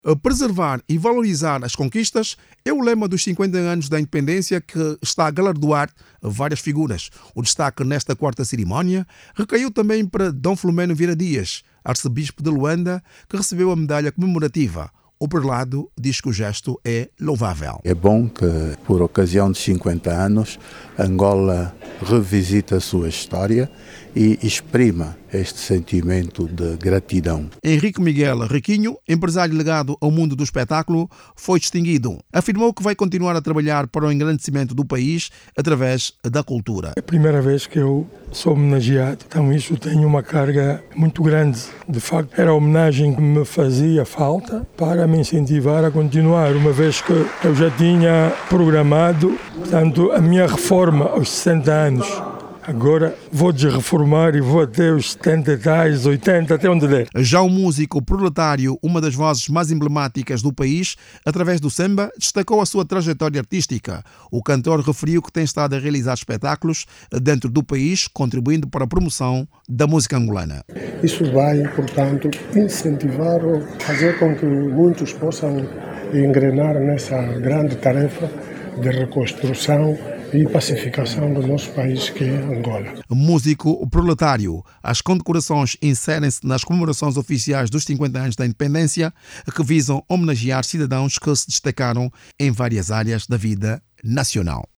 A cerimónia de condecorações no âmbito dos cinquenta anos de independência de Angola foi prestigiada ontem, sábado(19), pela Primeira-Dama, Ana Dias Lourenço, a Vice-Presidente da República, membros do poder legislativo, judicial, executivo, entre outros convidados, foram condecoradas outras personalidades nacionais e internacionais reconhecidas com a Medalha de Honra. Clique no áudio abaixo e ouça a reportagem